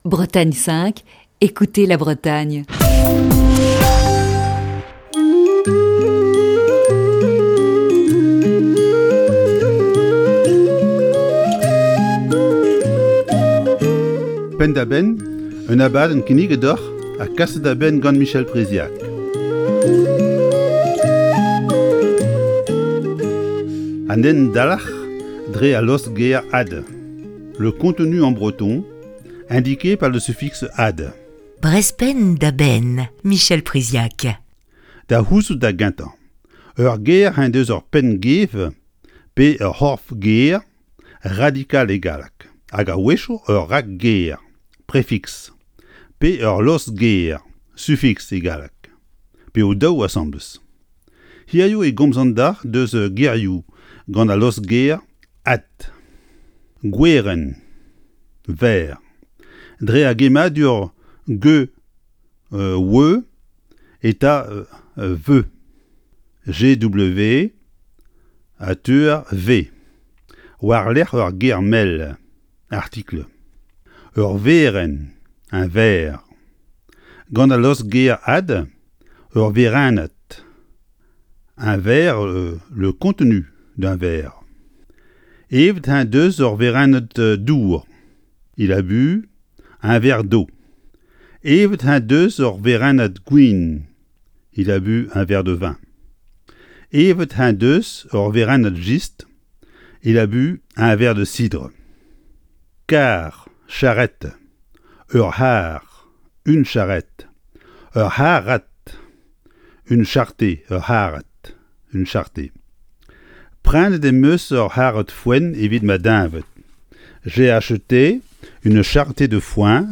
Chronique du 4 février 2021.